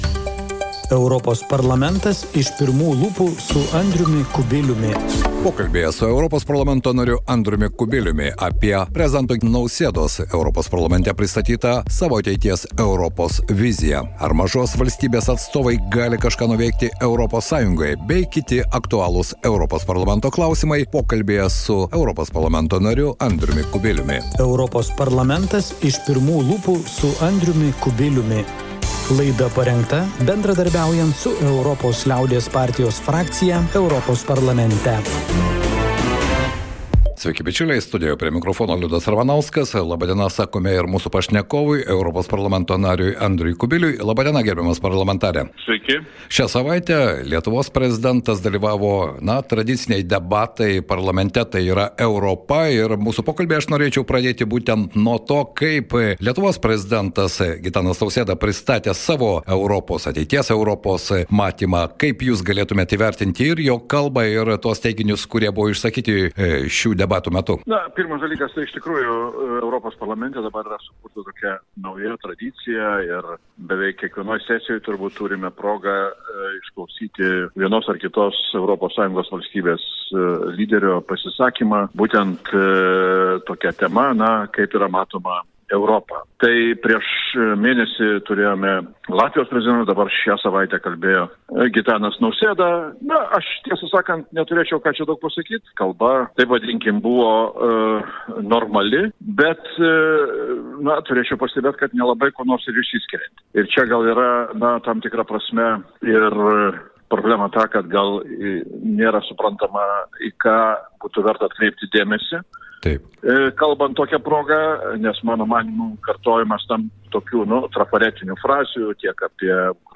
Apie Prezidento Gitano Nausėdos vizitą Europos Parlamente Strasbūre, kovo 13-16 dienomis čia vykusios plenarinės sesijos aktualijas, pokalbis su europarlamentaru, Europos liaudies partijos frakcijos nariu, Andriumi Kubiliumi.